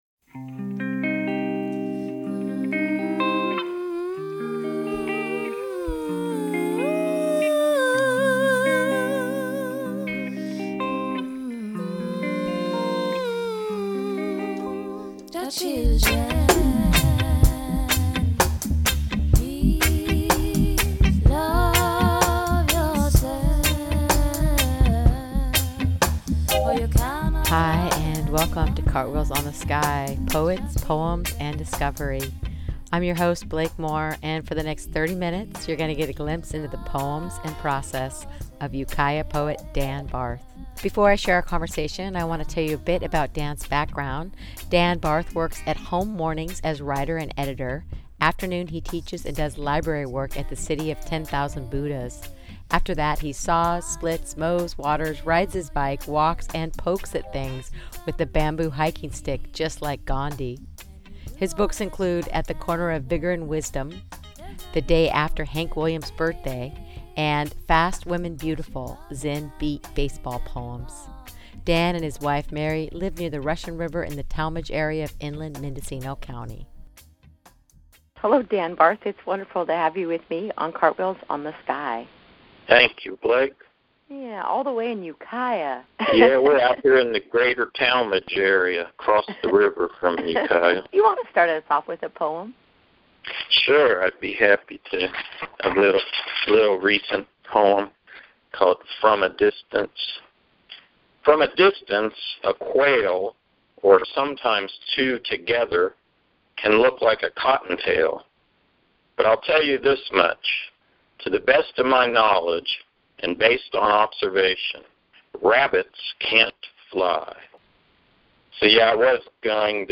This show airs live from 7-7:30pm, Saturday, October 17 on KGUA FM 88.3 Gualala